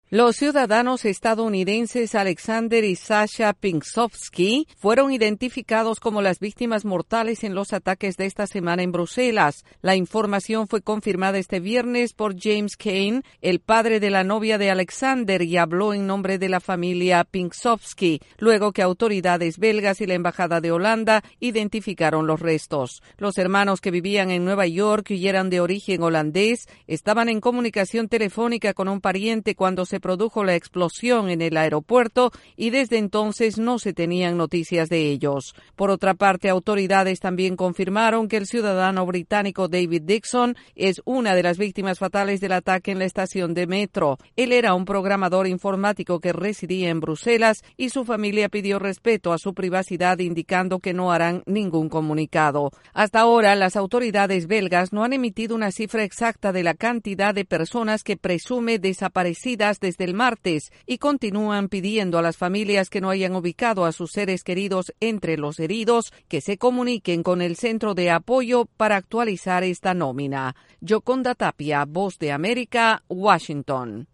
Se identifican a víctimas estadounidenses en el atentado terrorista de Bruselas. Desde la Voz de América en Washington DC informa